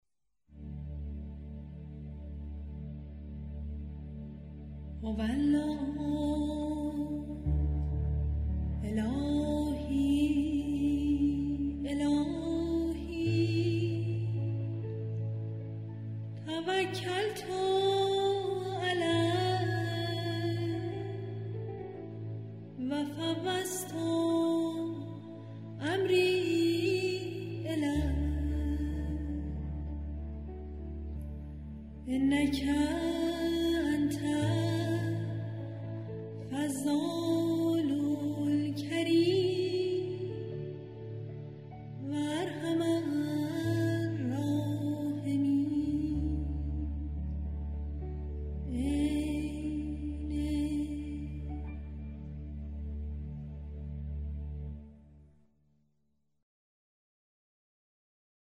مناجات های صوتی